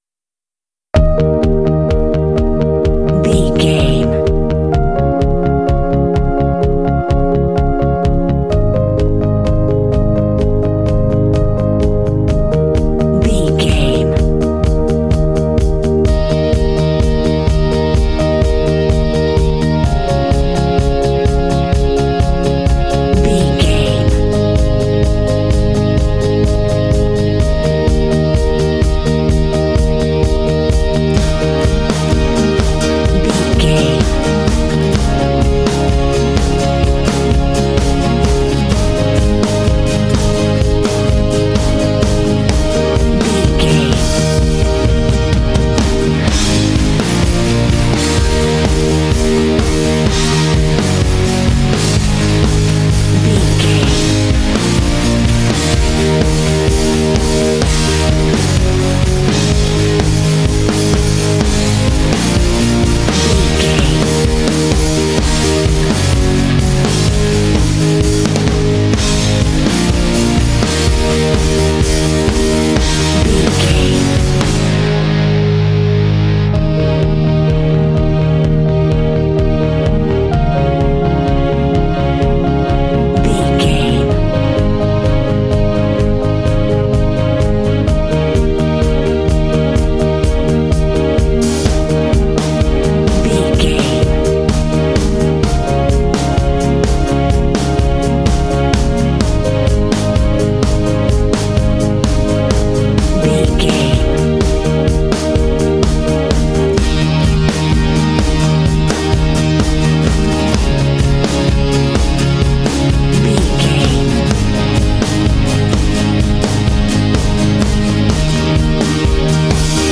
Aeolian/Minor
E♭
driving
bouncy
happy
groovy
bright
motivational
drums
electric guitar
bass guitar
strings
rock
alternative rock
indie